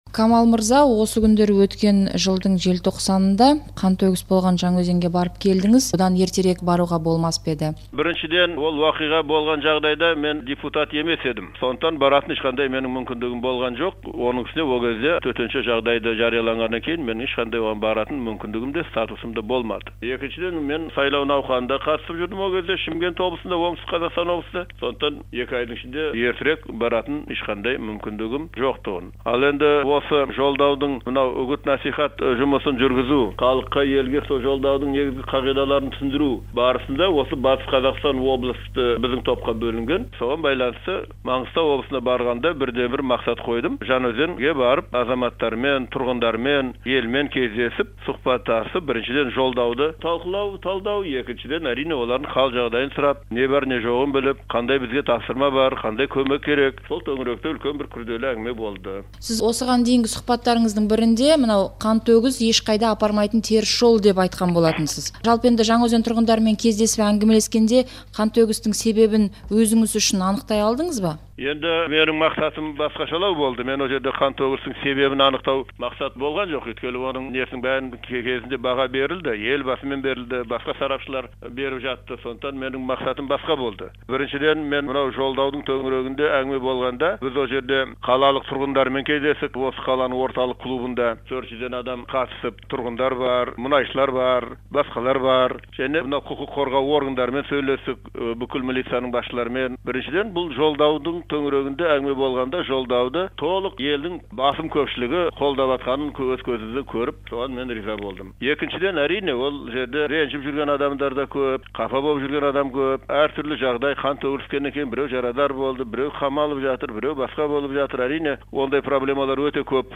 Депутат Камал Бұрхановтың Жаңаөзенге қатысты сұқбатын тыңдаңыз